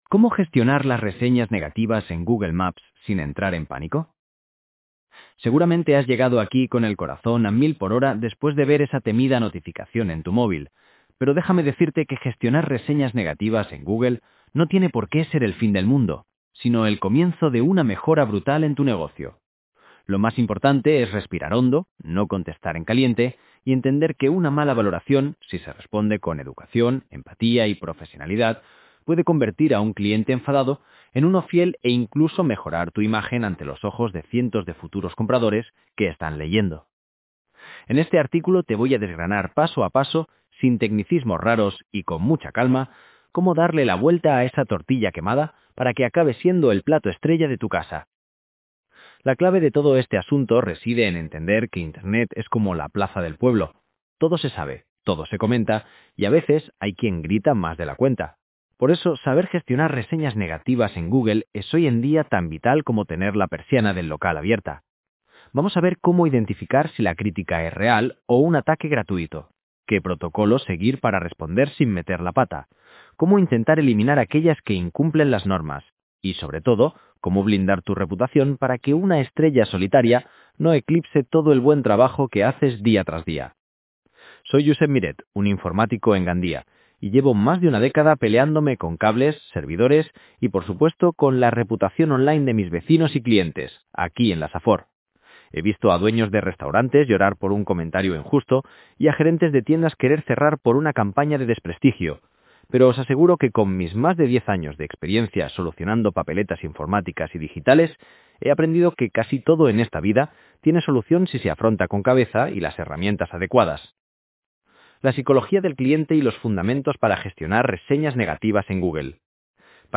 Dale al play para escuchar el artículo Gestionar reseñas negativas en Google